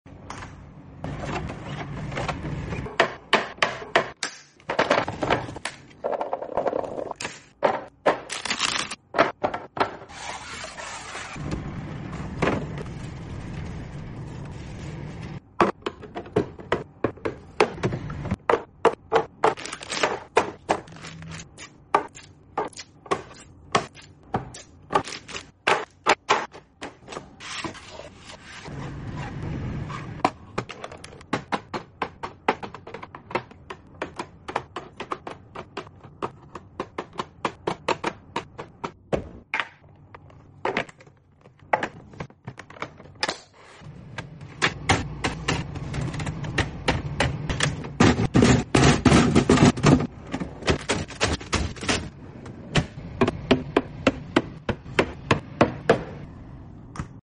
Full ASMR healthy fridge restock